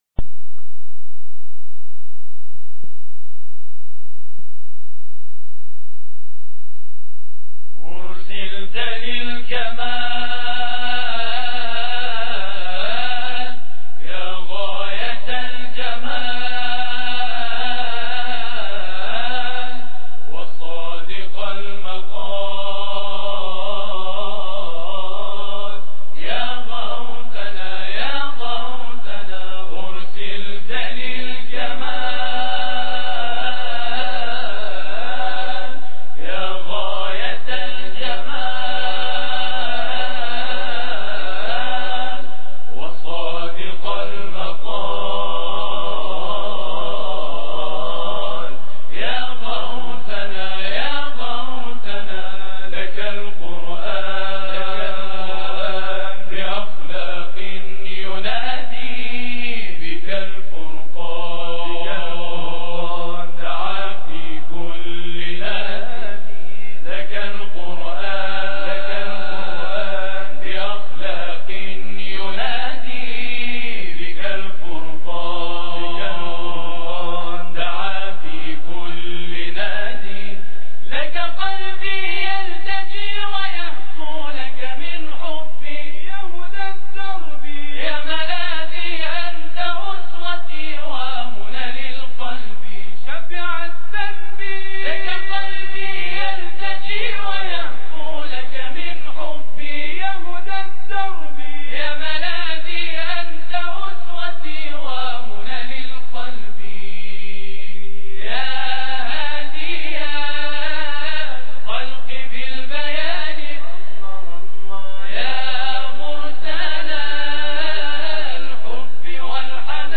تواشیح
گروه تواشیح سبطین اهواز
ابن تواشیح با کیفیت بسیار پایین سال 88 ضبط شده بود